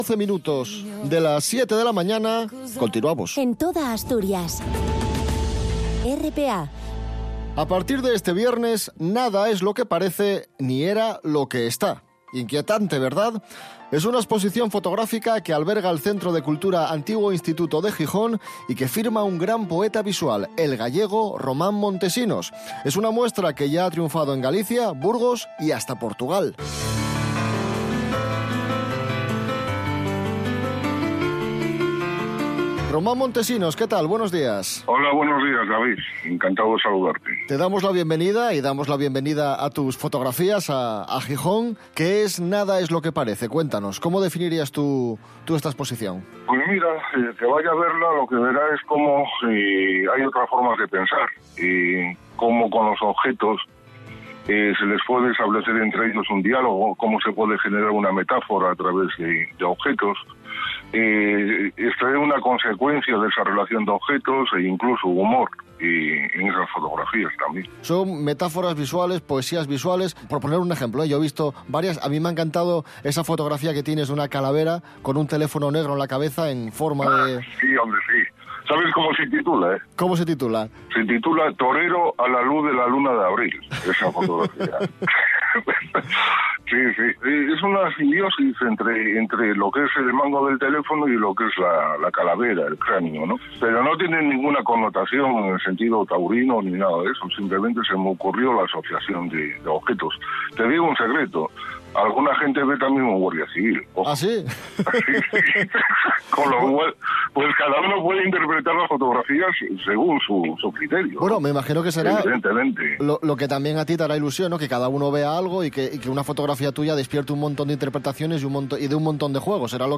Pulsando en la imagen, o en la barra inferior, se puede escuchar el audio de la entrevista.